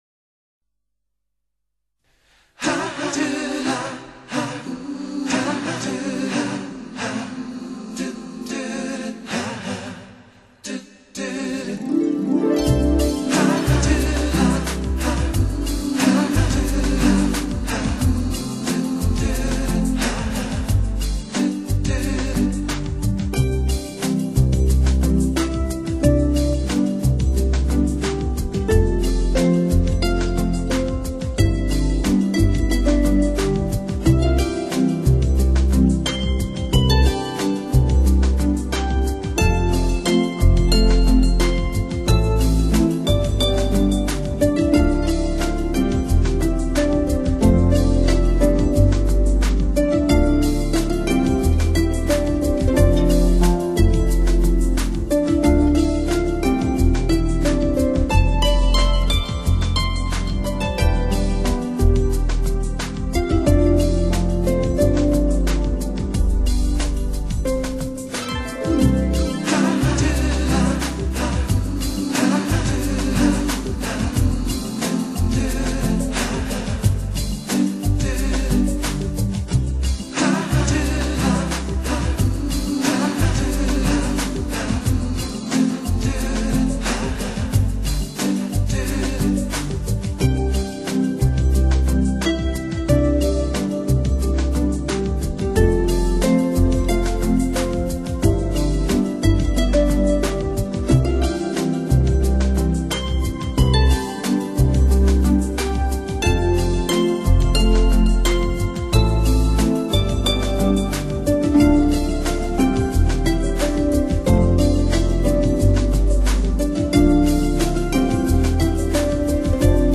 风靡世界的竖琴美人，优雅的旋律，现代的节奏，独特个性化的竖琴流行曲，演绎着一首首浪漫情调。